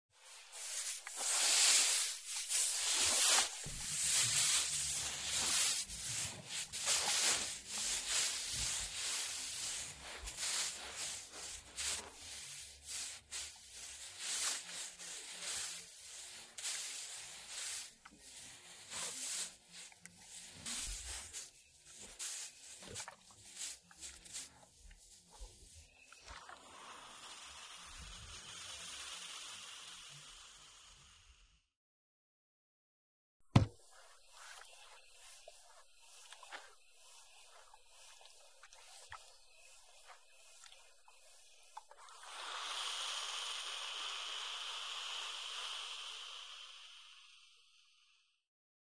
Звуки пара
Шум пара при обработке одежды парогенератором